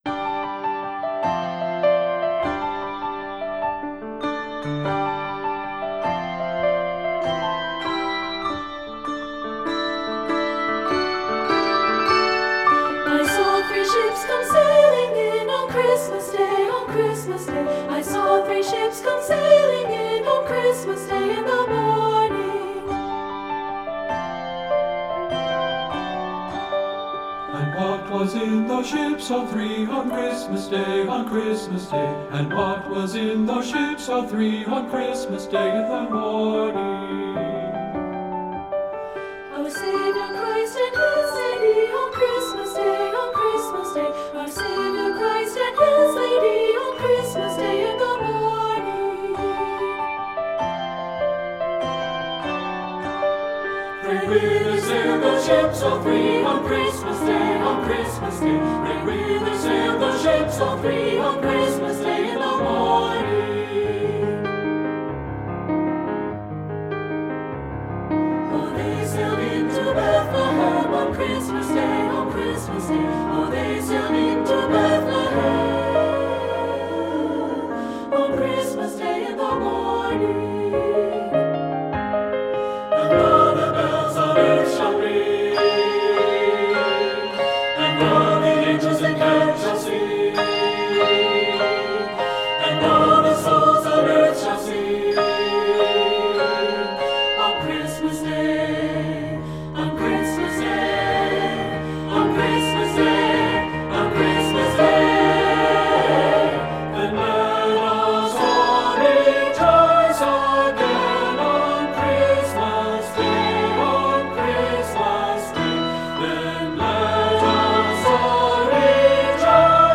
Composer: Traditional English Carol
Voicing: SAB